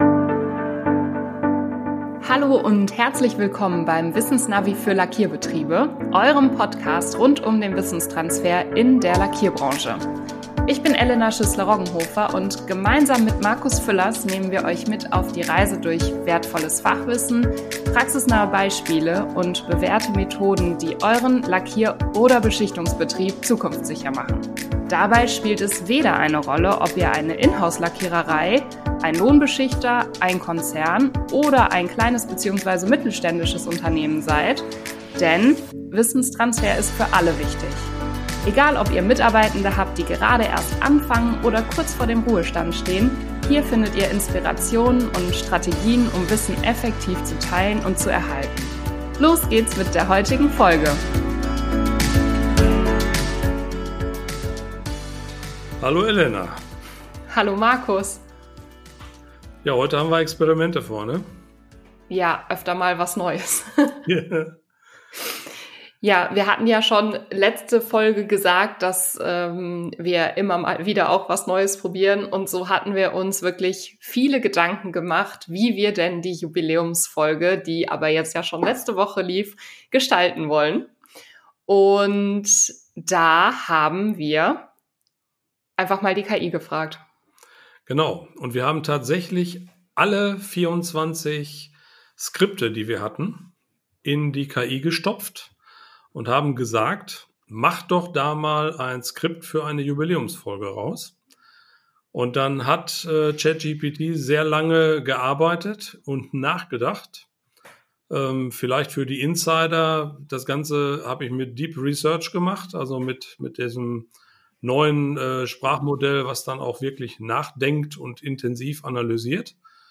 Wir haben das Drehbuch erstellen lassen aus allen bisherigen Podcast folgen und lesen dieses vor. Es hat Spass gemacht, aber das Ergebnis der KI hatte viele Fehler und Halluzinationen.